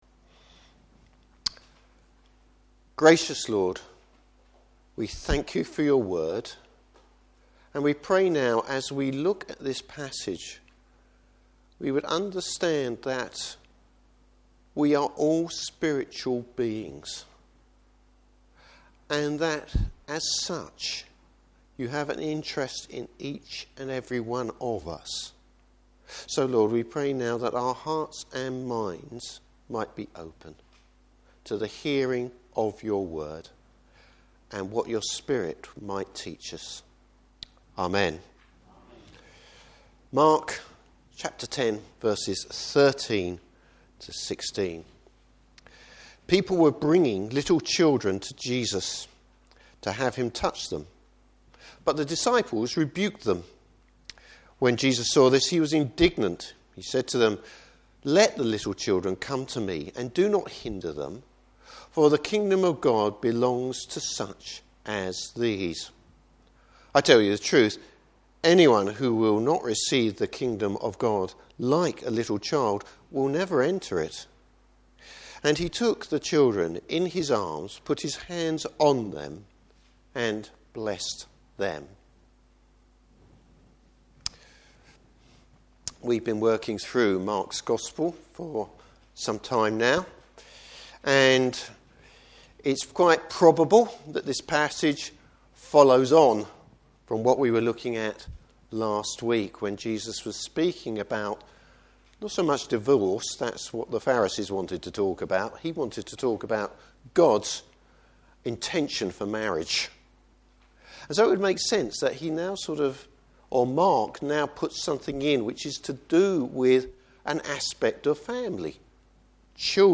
Service Type: Morning Service The importance of everyone where God is concerned.